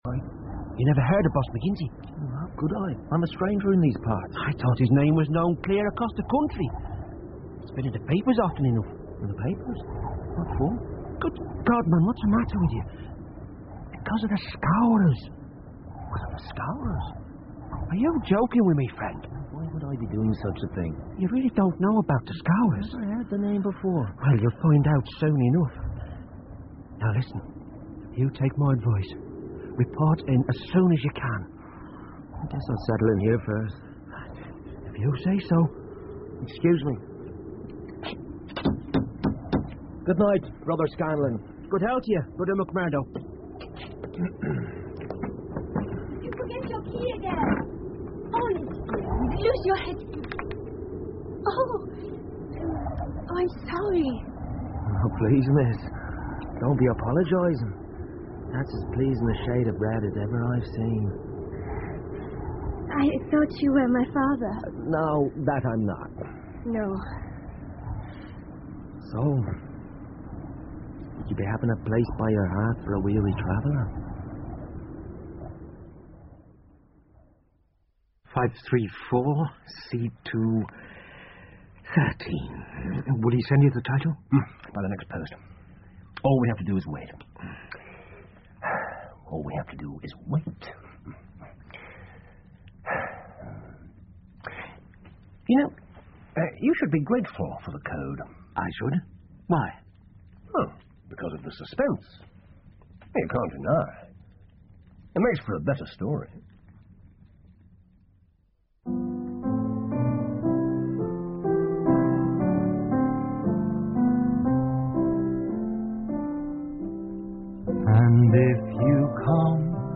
福尔摩斯广播剧 The Valley Of Fear - Part 01-3 听力文件下载—在线英语听力室